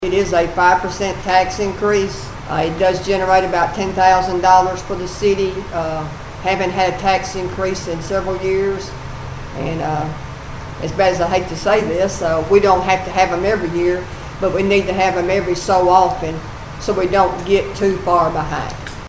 Meanwhile at the City of Sharon board meeting last night, the aldermen along with Mayor Donna Stricklin, approved the 2025-26 budget.
The board accepted a tax increase of 5%, bringing the new tax rate to $1.37, resulting in $10,000 increase for the city.  Mayor Stricklin explains how proactive this decision is for Sharon.